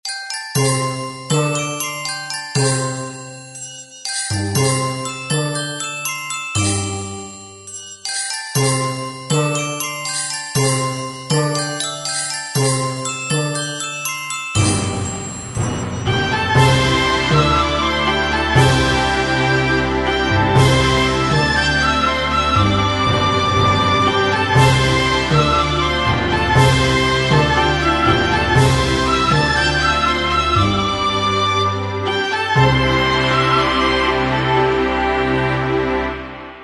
Catégorie Alarme/Reveil